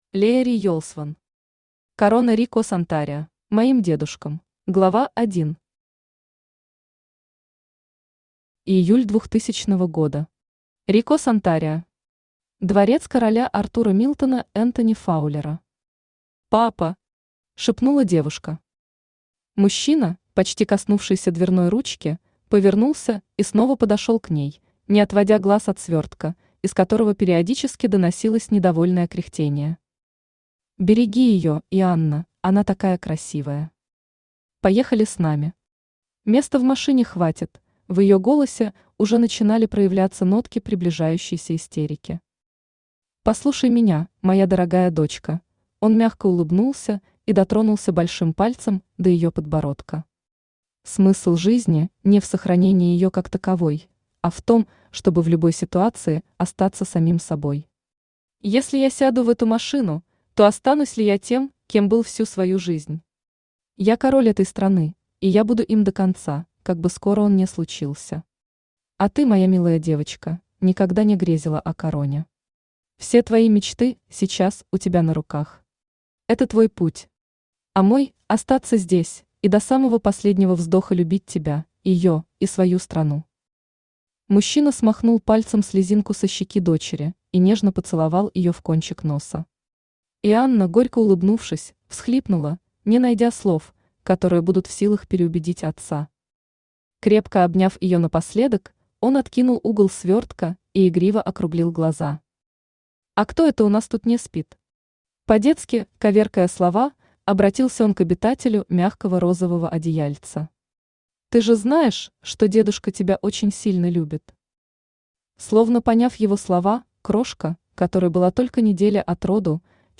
Аудиокнига Корона Рико-Сантарио | Библиотека аудиокниг
Aудиокнига Корона Рико-Сантарио Автор Лея Риелсвон Читает аудиокнигу Авточтец ЛитРес.